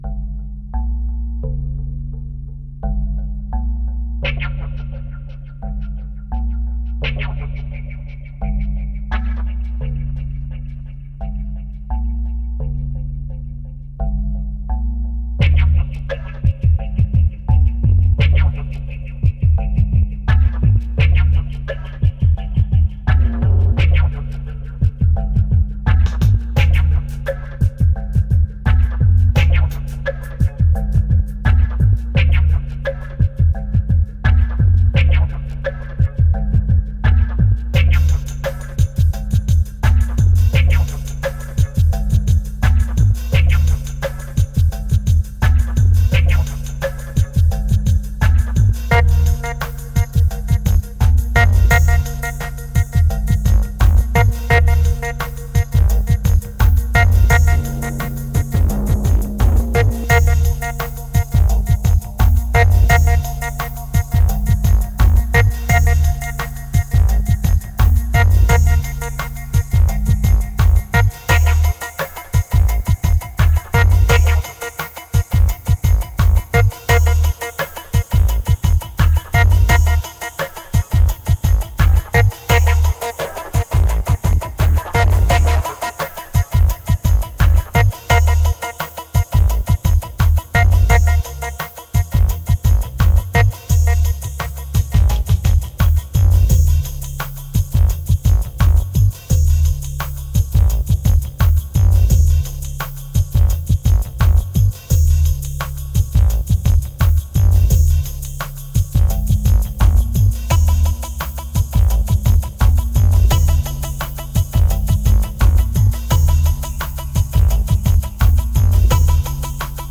2117📈 - 20%🤔 - 86BPM🔊 - 2012-10-25📅 - -68🌟